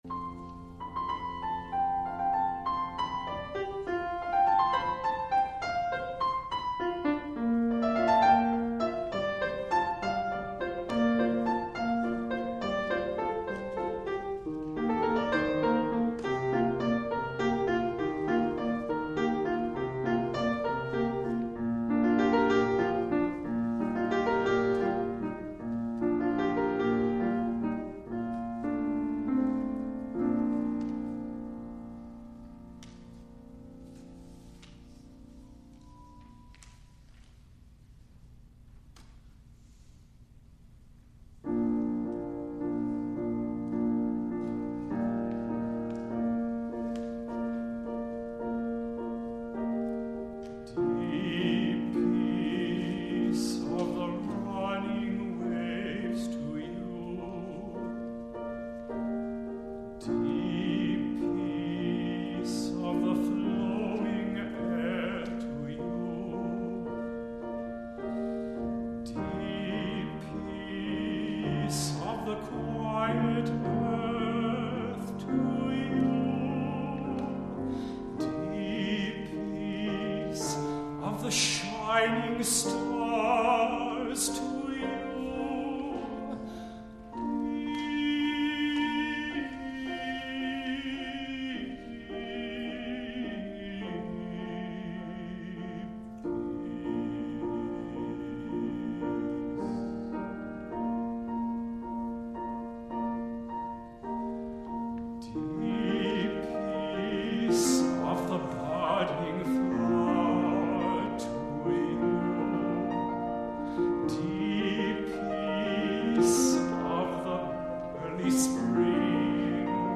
for Bass-Baritone, Cello, and Organ (2004)